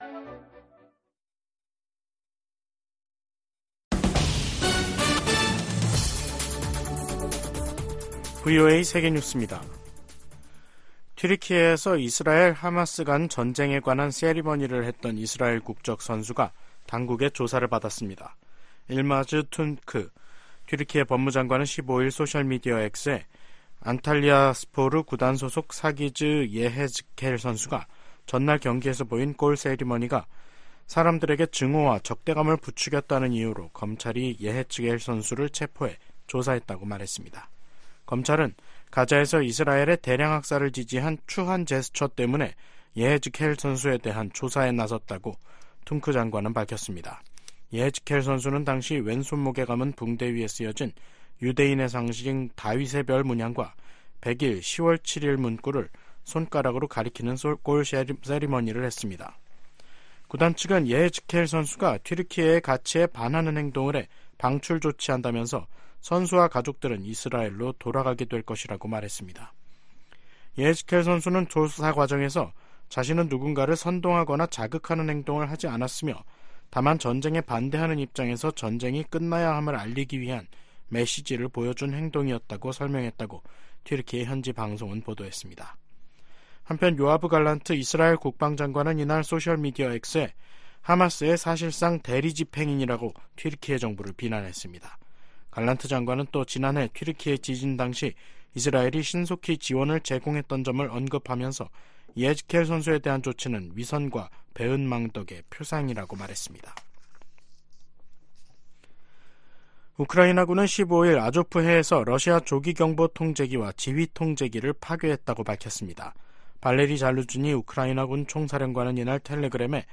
VOA 한국어 간판 뉴스 프로그램 '뉴스 투데이', 2023년 1월 15일 3부 방송입니다. 북한은 신형 고체연료 추진체를 사용한 극초음속 중장거리 탄도미사일(IRBM) 시험 발사에 성공했다고 발표했습니다. 미국은 북한의 새해 첫 탄도미사일 발사를 유엔 안보리 결의 위반이라며 대화에 나설 것을 거듭 촉구했습니다. 중국 선박이 또다시 북한 선박으로 국제기구에 등록됐습니다.